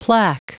Prononciation du mot plaque en anglais (fichier audio)
Prononciation du mot : plaque